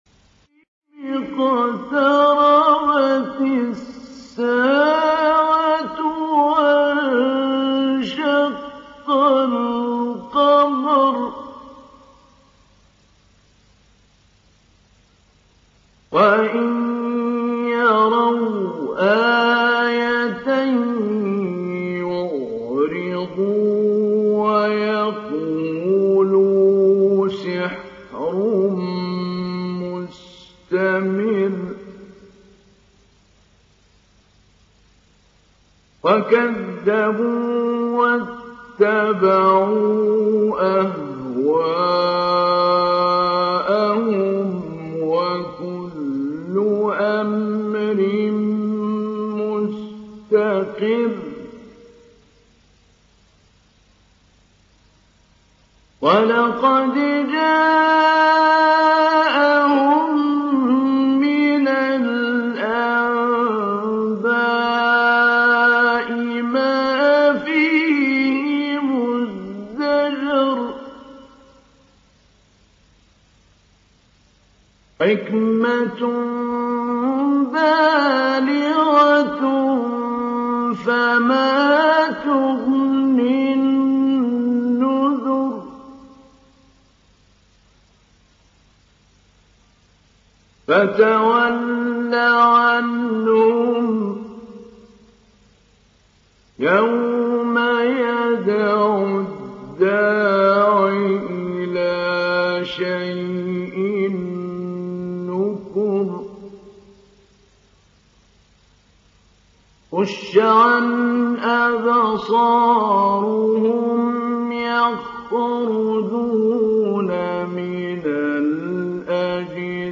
Download Surat Al Qamar Mahmoud Ali Albanna Mujawwad